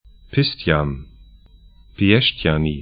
Pistyan 'pɪstjan Piešt’any 'pĭɛʃtjani sk Stadt / town 48°36'N, 17°50'E